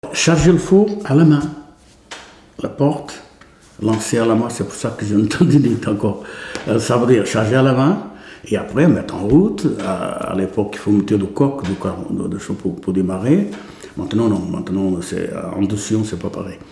Figure 12 : Extrait vidéo du témoignage d’un fondeur.